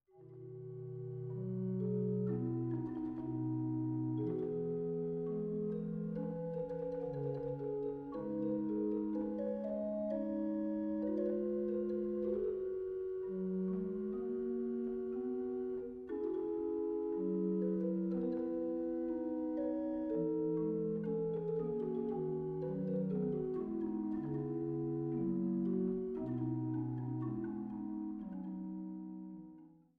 Mezzosopran, Orgel
Bariton, Orgel, Cembalo
Laute
Violoncello, Viola da gamba